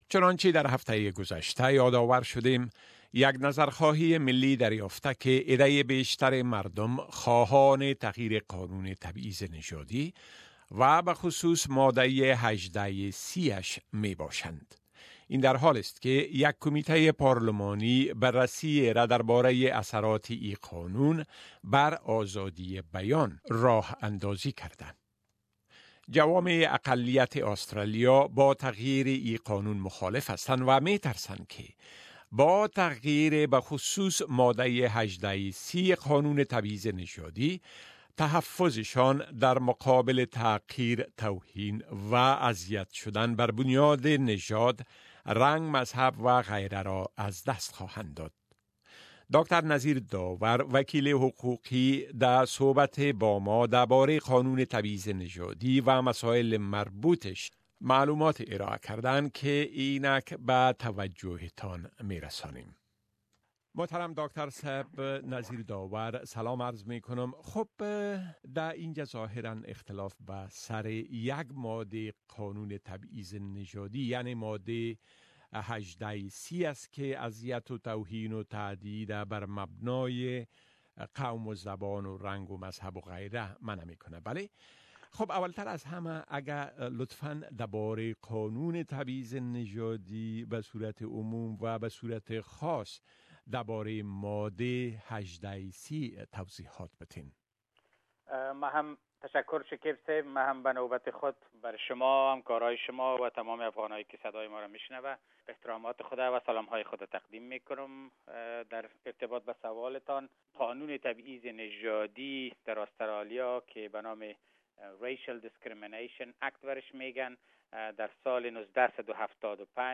در صحبی با ما دربارۂ اين قانون و مسايل مربوط به آن معلوماتی داده اند كه توجۂ تانرا به آن جلب ميكنيم.